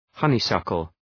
Προφορά
{‘hʌnı,sʌkəl}